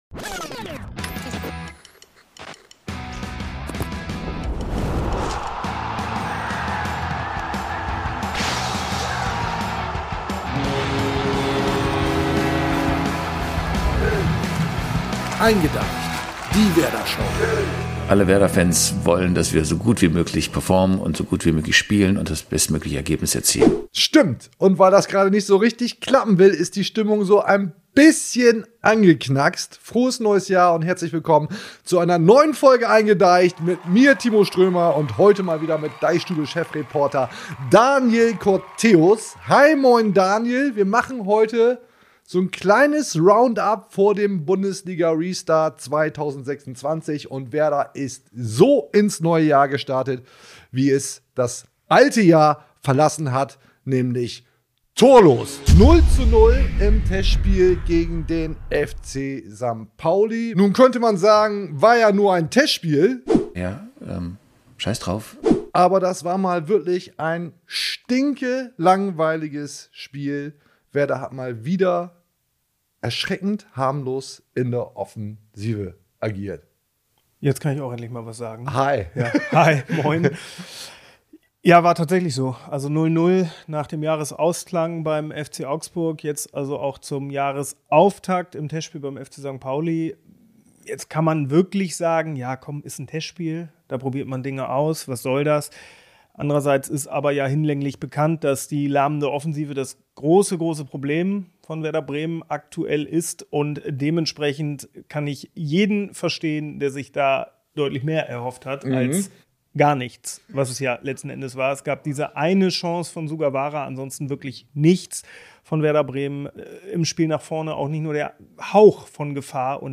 Denn nn der Werder-Podcast-Show eingeDEICHt, gesendet aus dem DeichStube-Office, erwartet Euch wie immer eine Vollgas-Veranstaltung vollgestopft mit den Themen, die die Fans des SV Werder Bremen beschäftigen.
Alberne Einspieler, allerlei Blödsinn, schlechte Wortwitze, dumme Sprüche, manchmal Werder-Expertise.